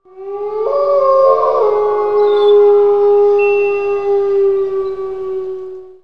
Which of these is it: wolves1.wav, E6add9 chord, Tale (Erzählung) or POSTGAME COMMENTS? wolves1.wav